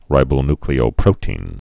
(rībō-nklē-ō-prōtēn, -tē-ĭn, -ny-)